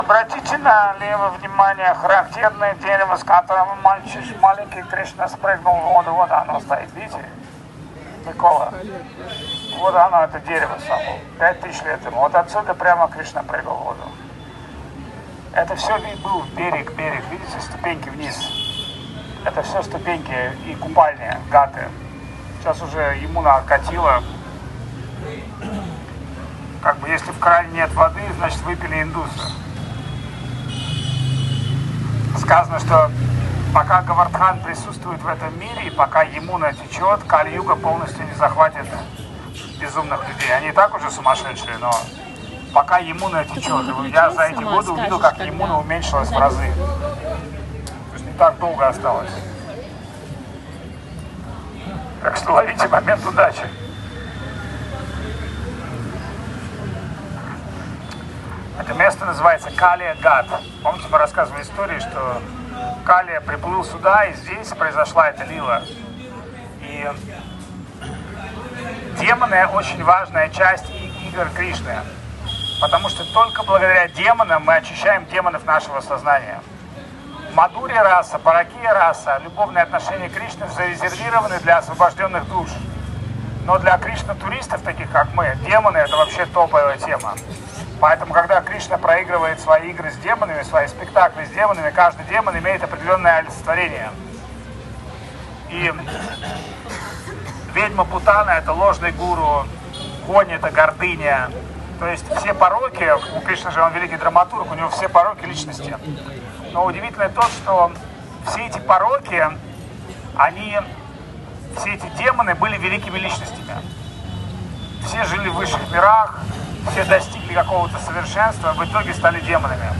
Вриндаван Дхама, Индия